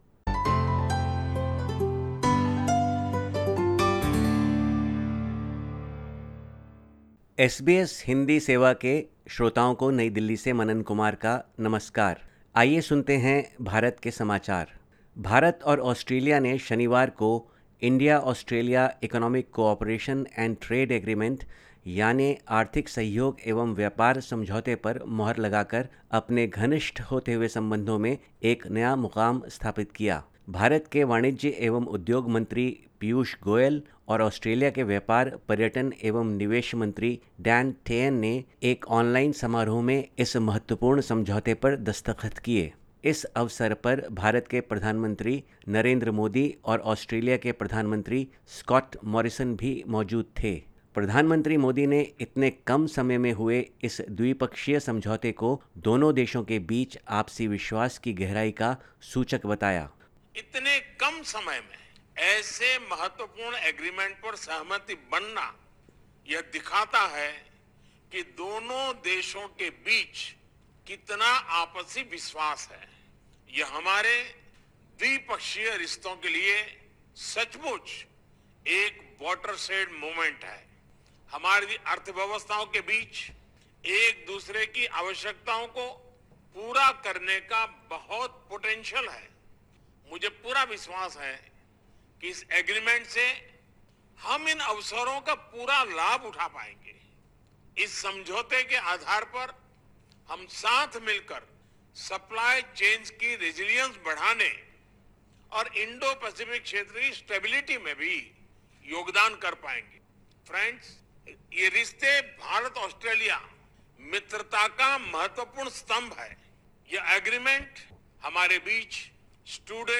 Listen to the latest SBS Hindi report from India. 04/04/2022